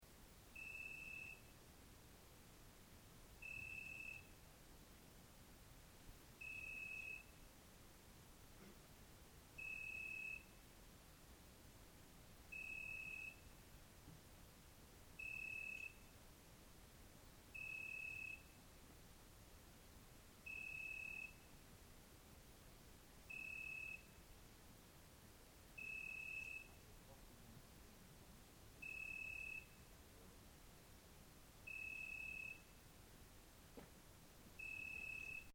Una cicala vicino alla tenda
Cicala.mp3